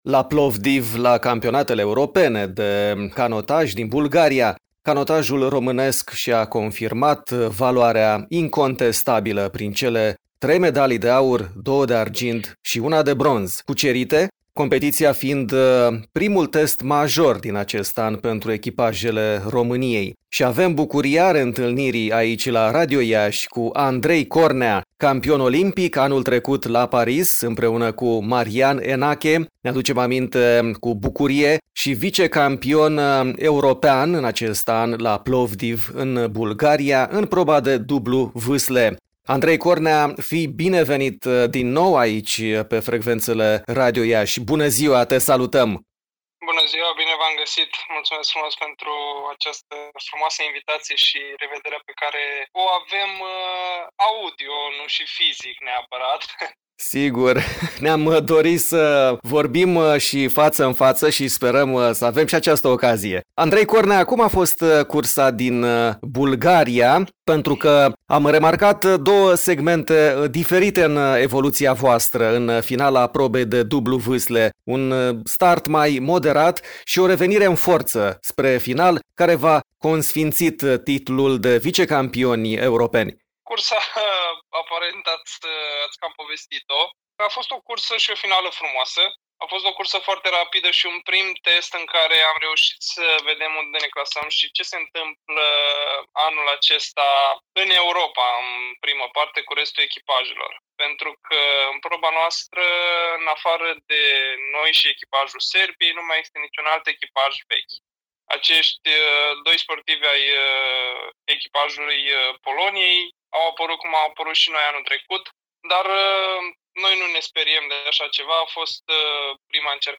Sursă: Andrei Cornea, vicecampion european și campion olimpic la canotaj.